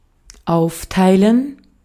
Ääntäminen
IPA : [ˈsplɪt]